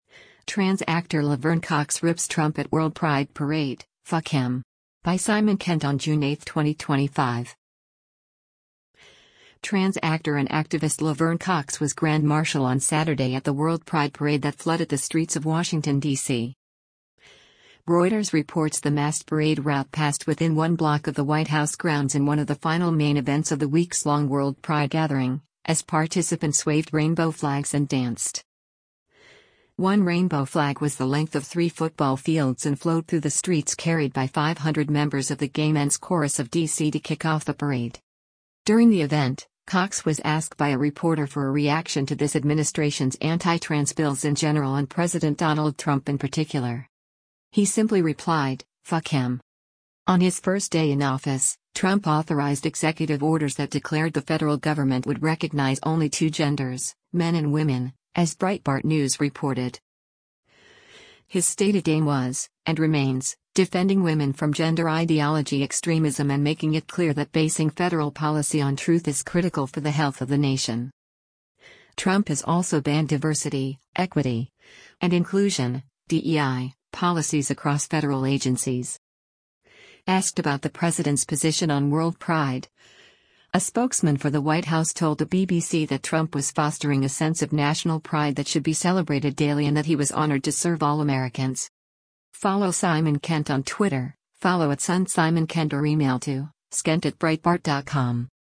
Trans actor and activist Laverne Cox was Grand Marshall on Saturday at the World Pride Parade that flooded the streets of Washington, DC.
During the event, Cox was asked by a reporter for a reaction to “this administration’s anti-trans bills” in general and President Donald Trump in particular.